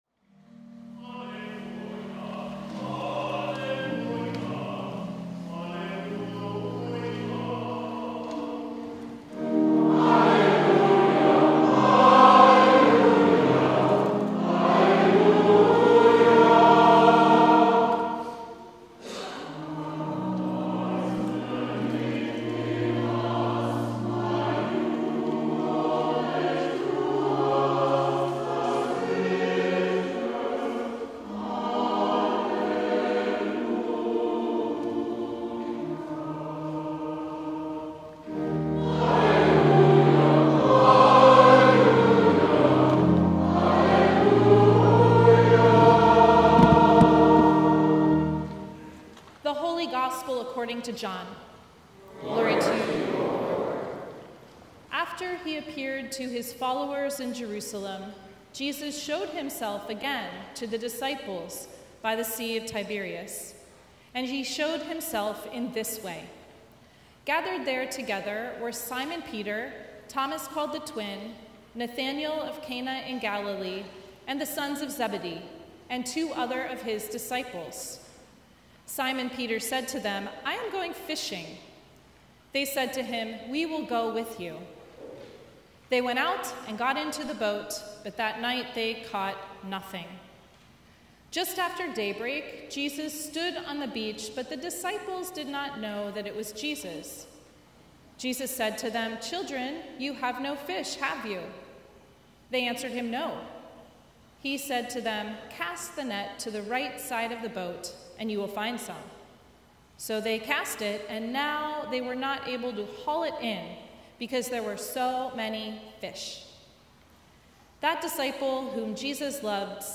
Sermons from Christ the King, Rice Village | Christ The King Lutheran Church